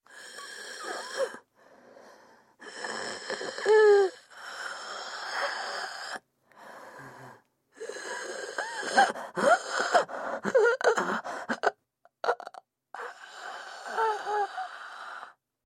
На этой странице собраны звуки хрипов разного характера: сухие и влажные, свистящие и жужжащие.
Хриплый вариант 2